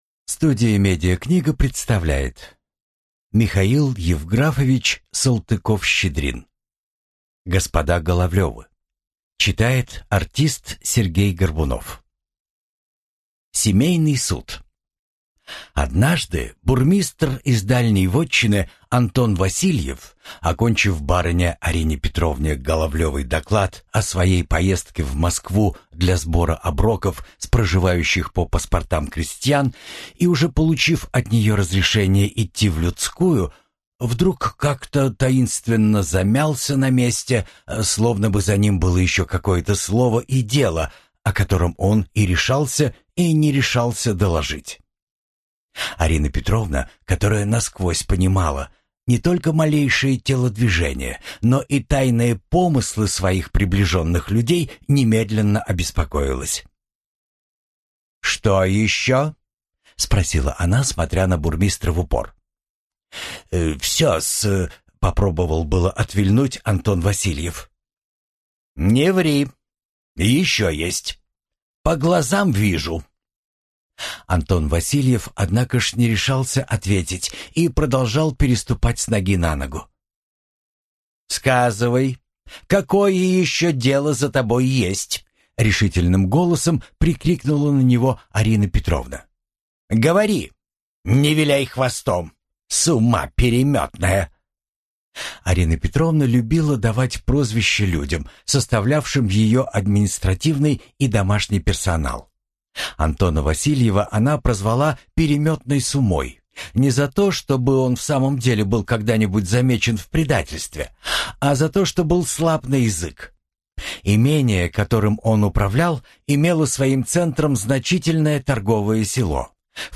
Аудиокнига Господа Головлевы | Библиотека аудиокниг